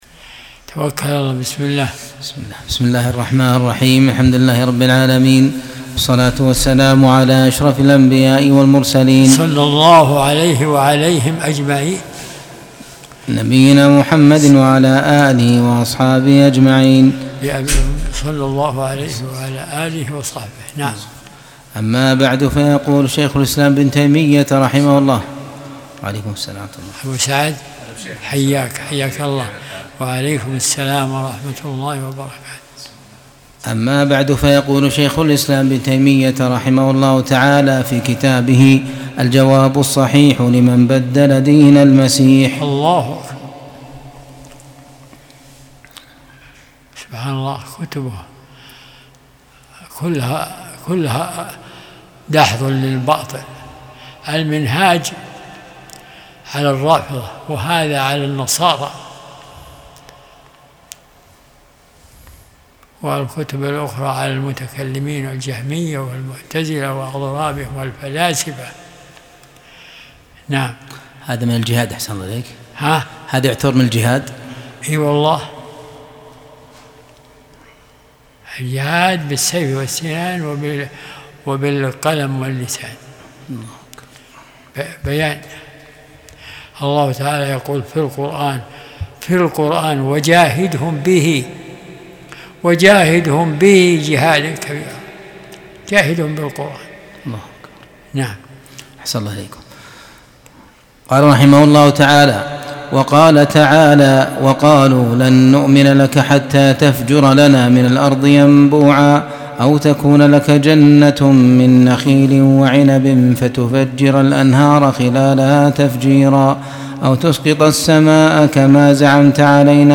درس الأحد 38